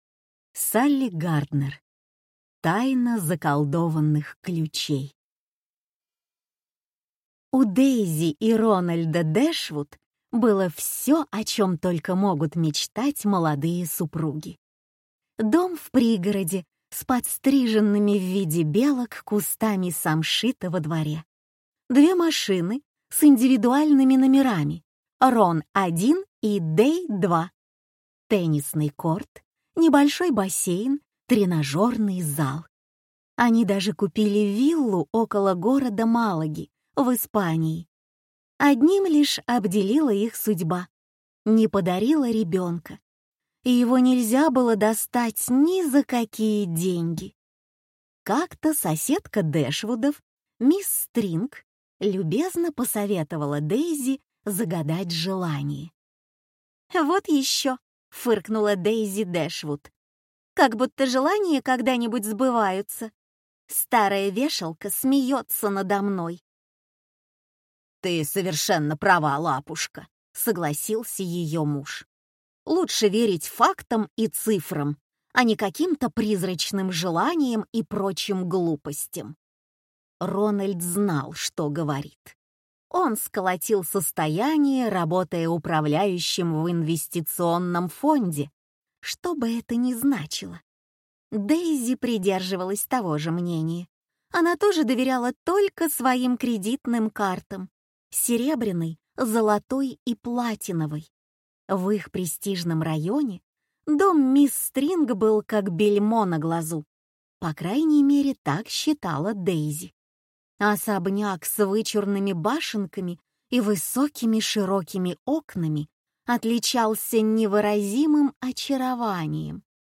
Аудиокнига Тайна заколдованных ключей | Библиотека аудиокниг